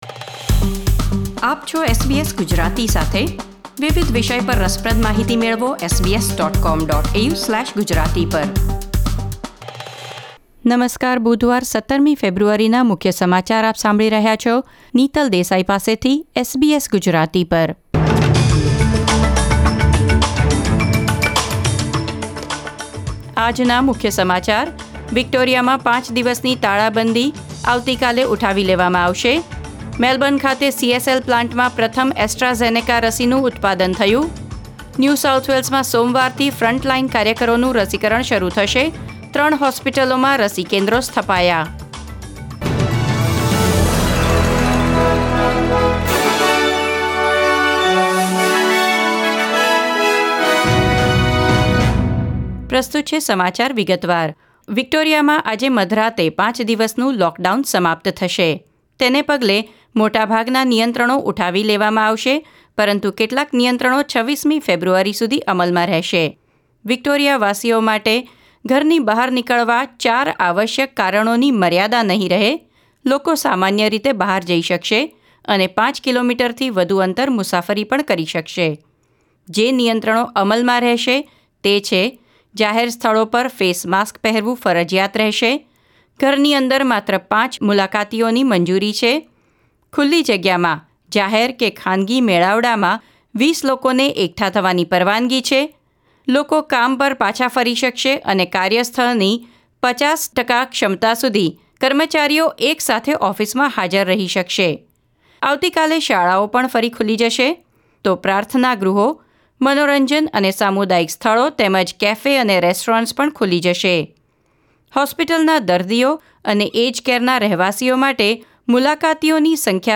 SBS Gujarati News Bulletin 17 February 2021